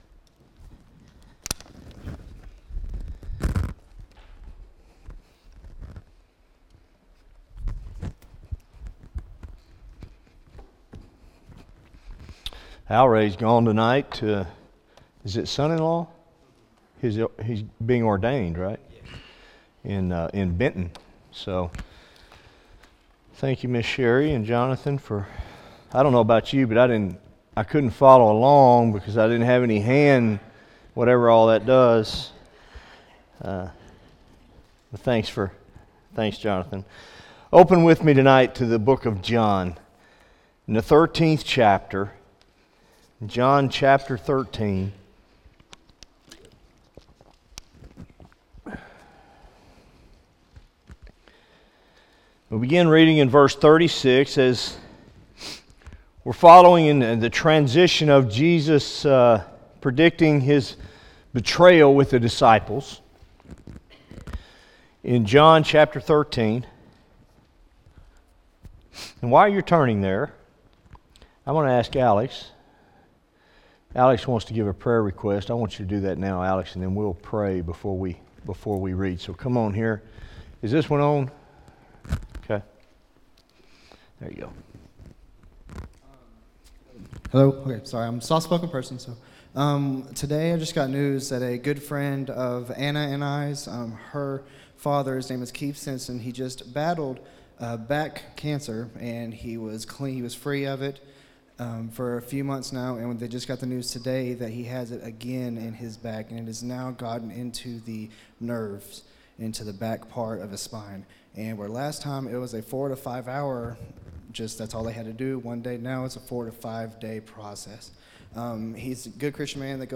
Morning Worship – “Where Are You Headed?” Mark 10:32-34
by Office Manager | Jan 30, 2017 | Bulletin, Sermons | 0 comments